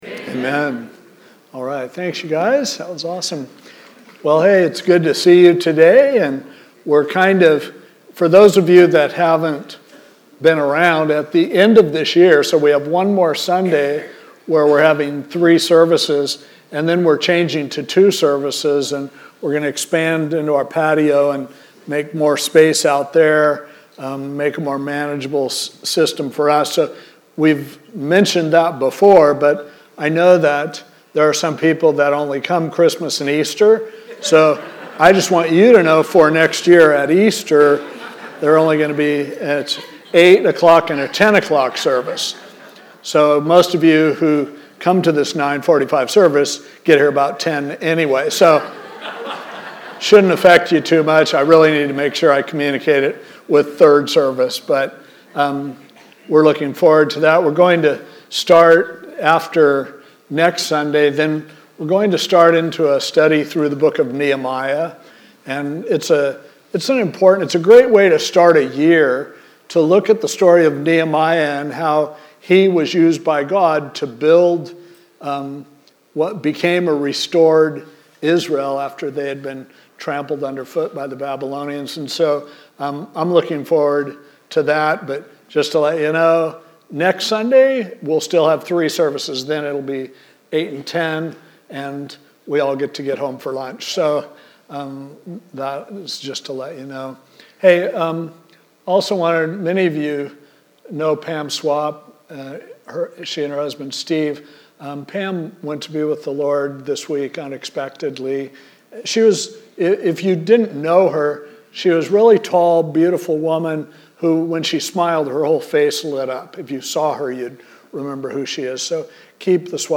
This podcast features weekly audio messages, special speakers, and special event audio at Pacific Hills Calvary Chapel.